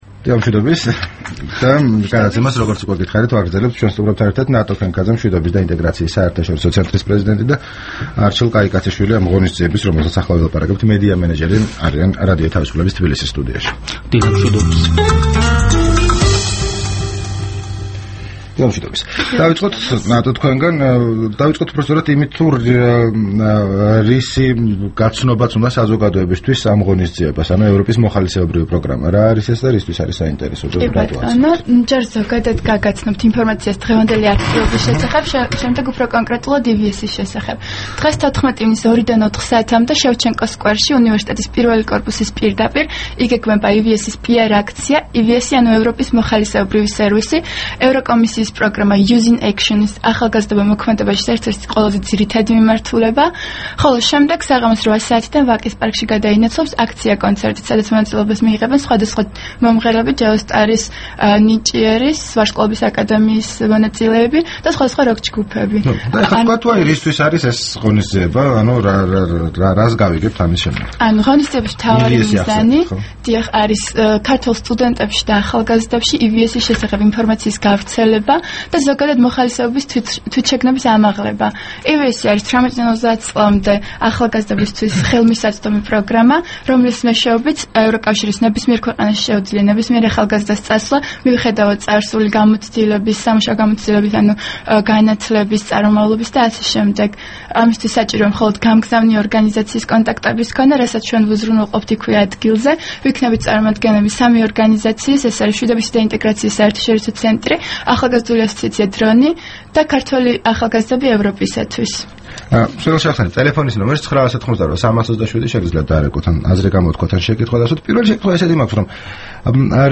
რადიო თავისუფლების თბილისის სტუდიაში